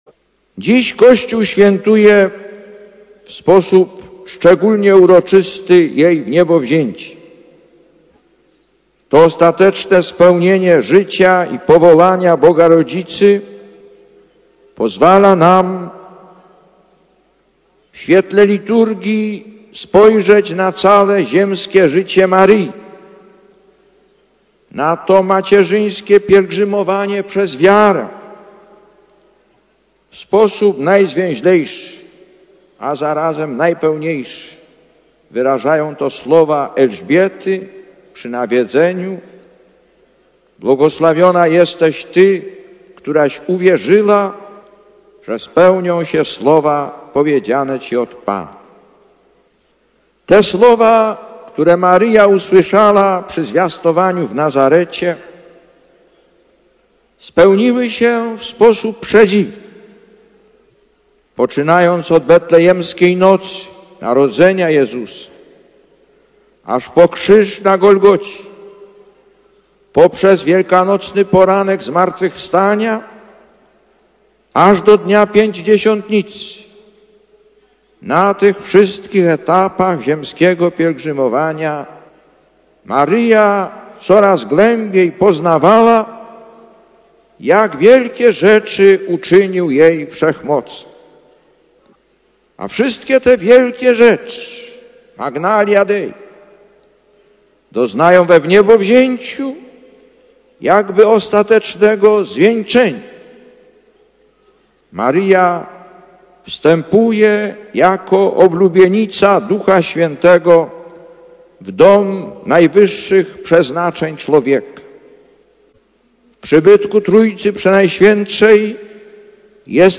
Lektor: Z homilii podczas Mszy św. na Jasnej Górze (Częstochowa, 15 sierpnia 1991 –